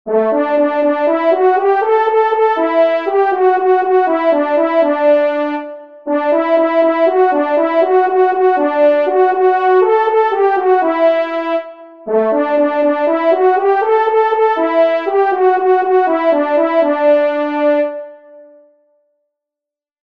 Musique Synthé “French Horns” (Tonalité de Ré